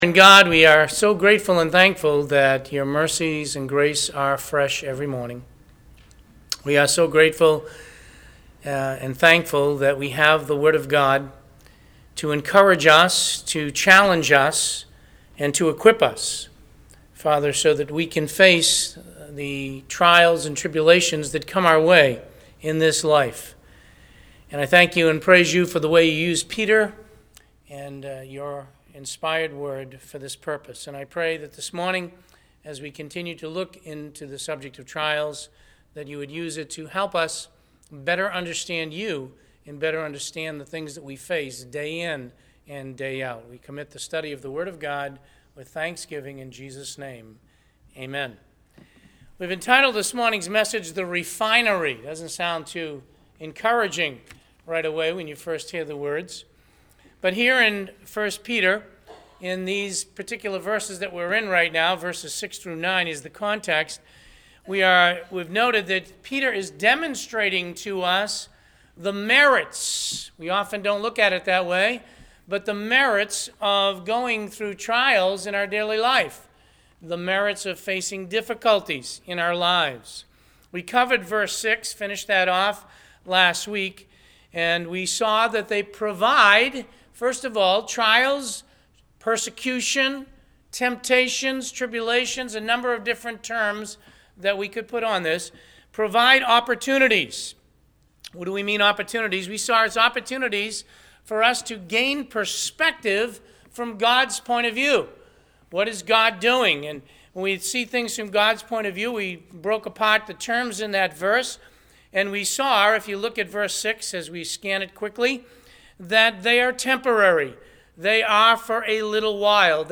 Listen to the sermon “The Refinery.”